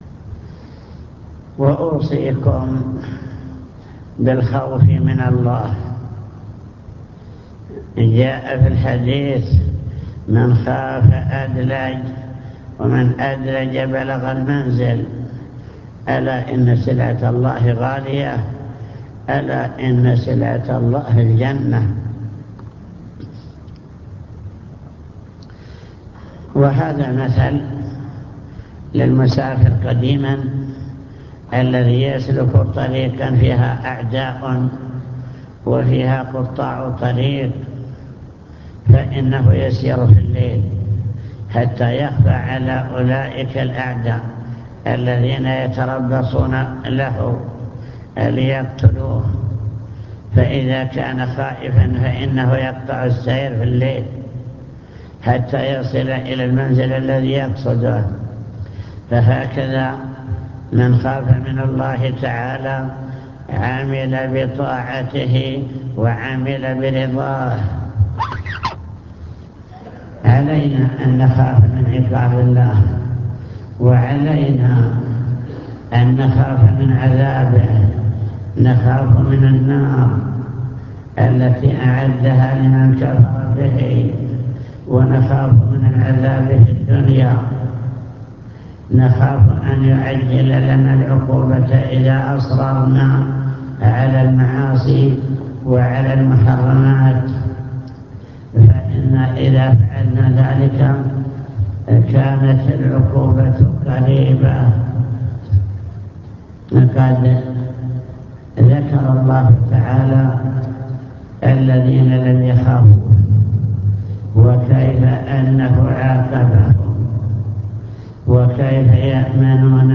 المكتبة الصوتية  تسجيلات - لقاءات  كلمة في معهد سلاح المدرعات